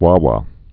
(wä)